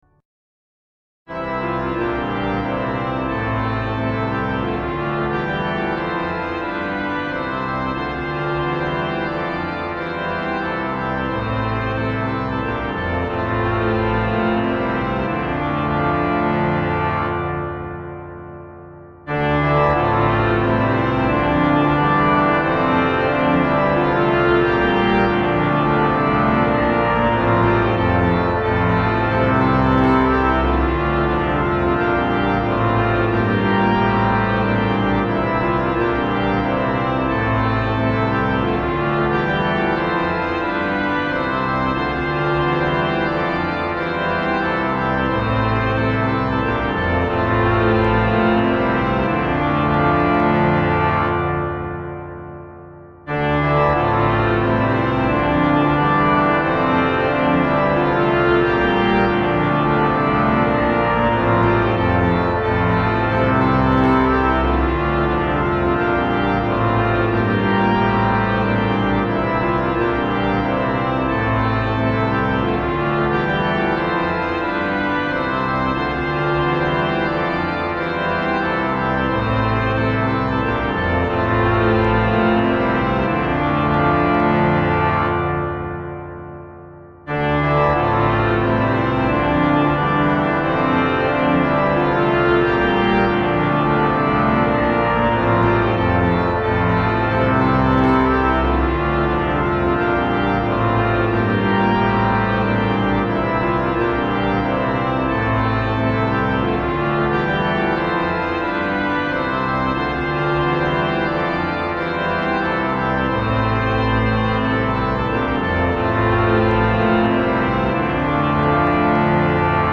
May We Cast the Vision Tune: King’s Weston, Ralph Vaughn Williams Traditional Hymn: At the Name of Jesus, Every Knee Shall Bow